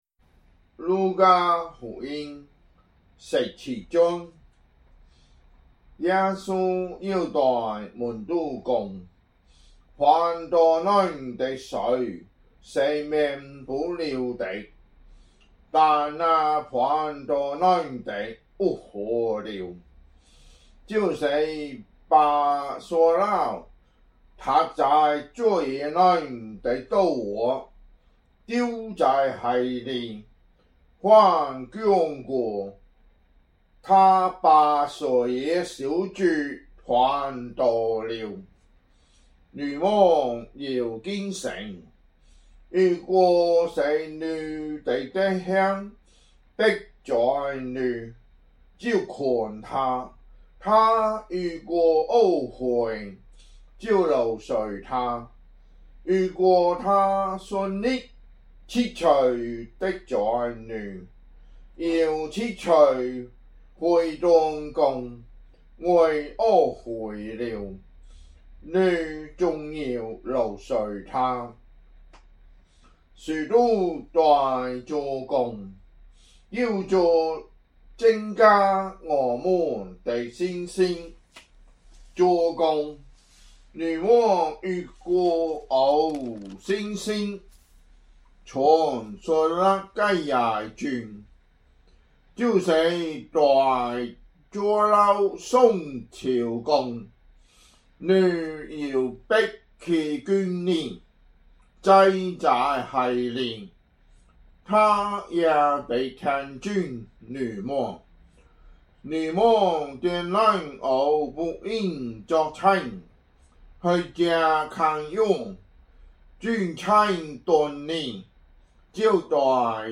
福州話有聲聖經 路加福音 17章